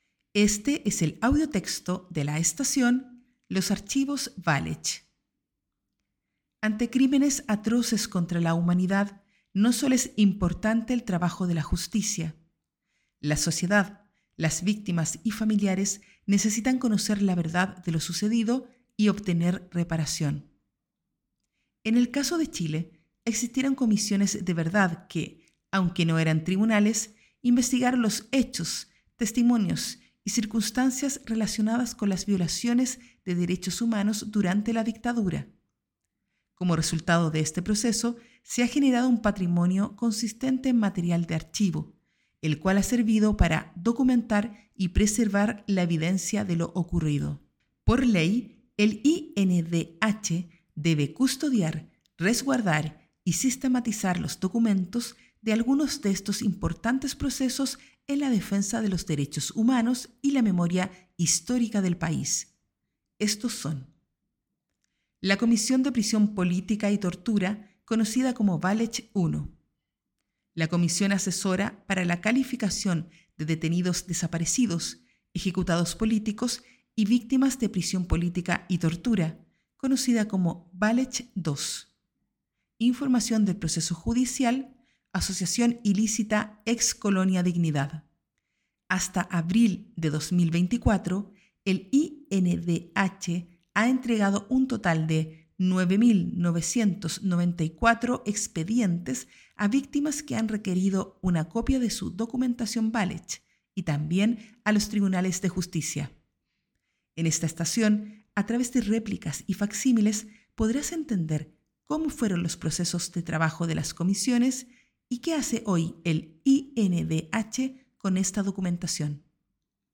Audiotexto